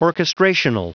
Prononciation du mot orchestrational en anglais (fichier audio)
Prononciation du mot : orchestrational